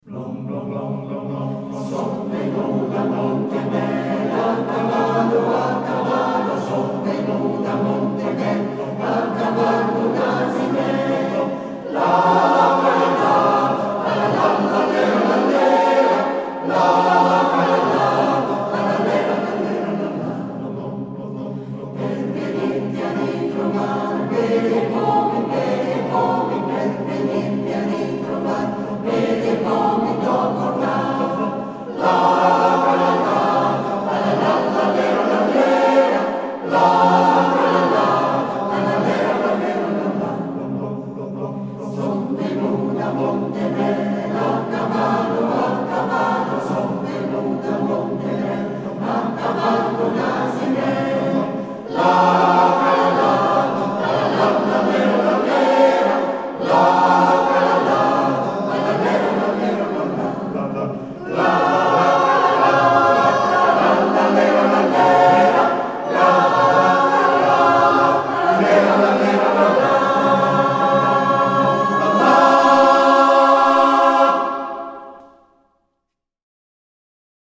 Arrangiatore: Molfino, Luigi (Armonizzatore)
Esecutore: Coro CAI Melegnano